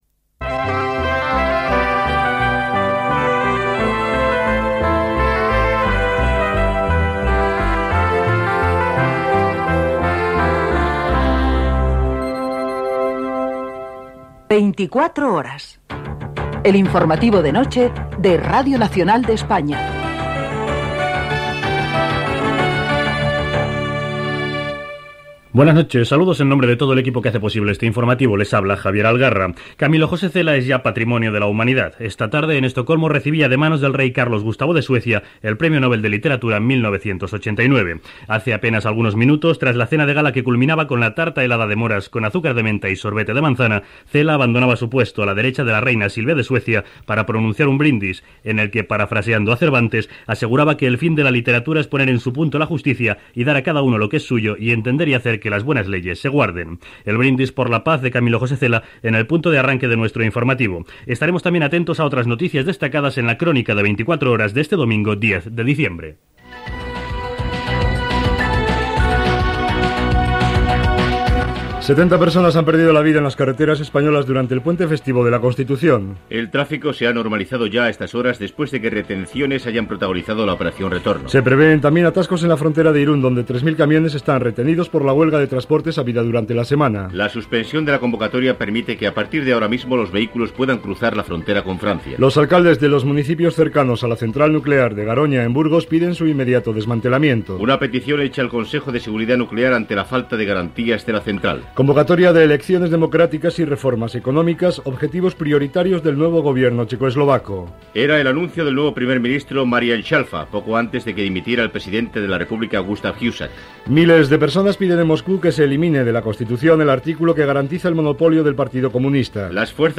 Sintonia de l'emissora, careta del programa, premi Nobel de literatura a Camilo José Cela, sumari, previsió del temps, indicatiu del programa.
Informatiu